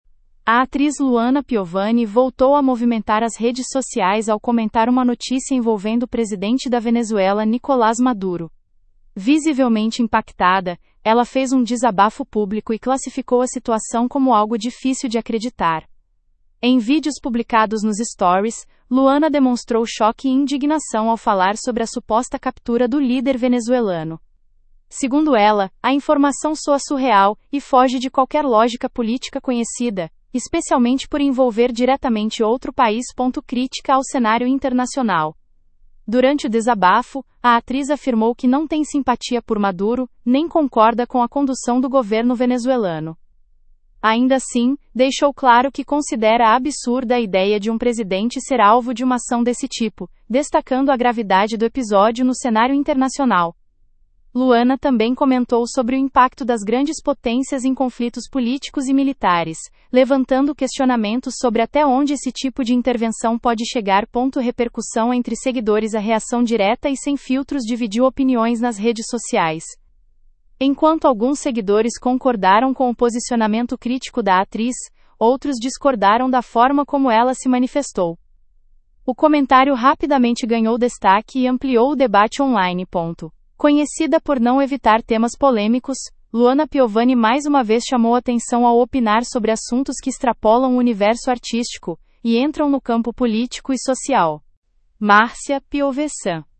Em vídeos publicados nos stories, Luana demonstrou choque e indignação ao falar sobre a suposta captura do líder venezuelano.